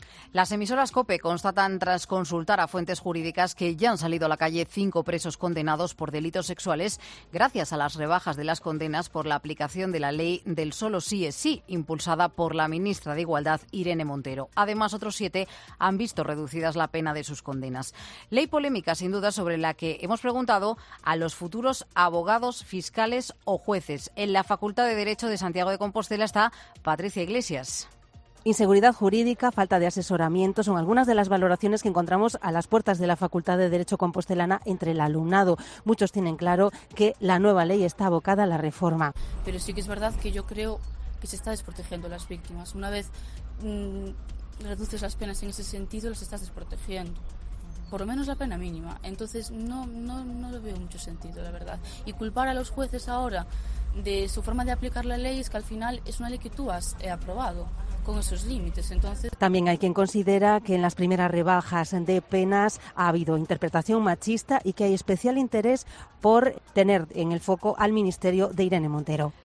Esto opinan alumnos de la Facultad de Derecho de Santiago de Compostela: se está creando inseguridad jurídica o falta de asesoramiento.